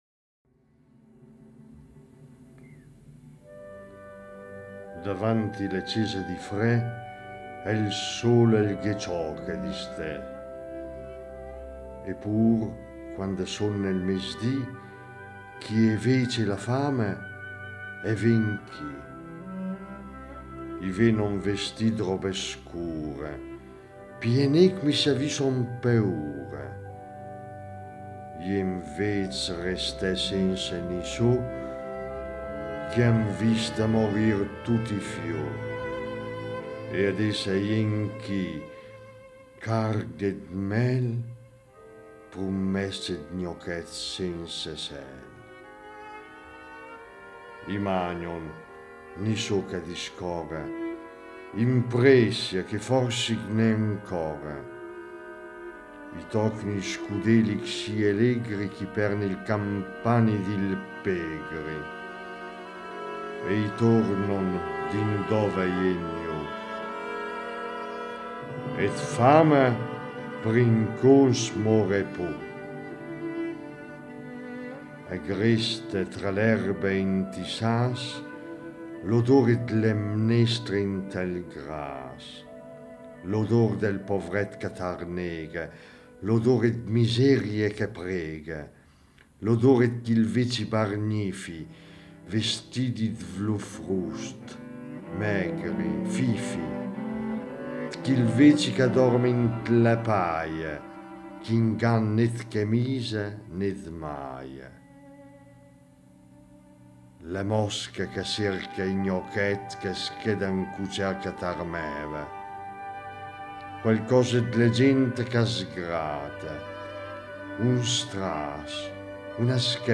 Accompagnamento musicale: Antonin Dvorak – Concerto per violoncello e orchestra in Si minore op. 104 – Adagio ma non troppo.